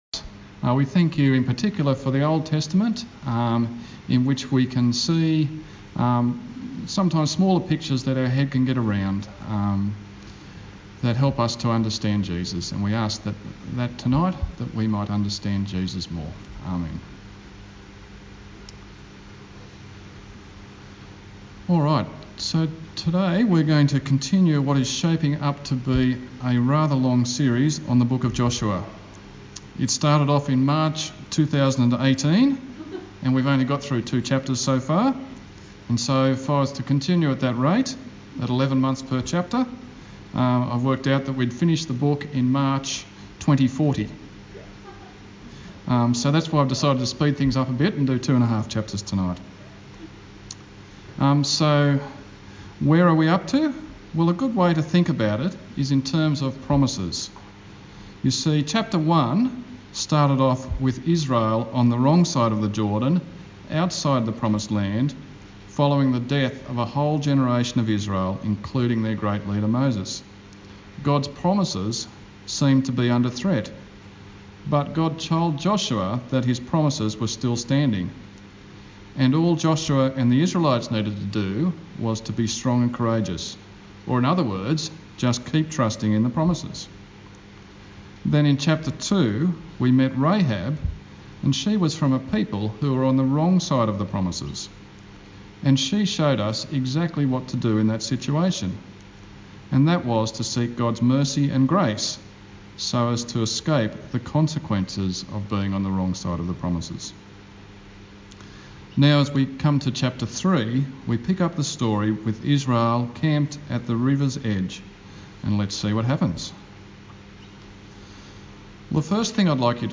Joshua Passage: Joshua 3:1-5:12 Service Type: TPC@5